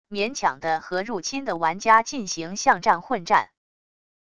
勉强的和入侵的玩家进行巷战混战wav音频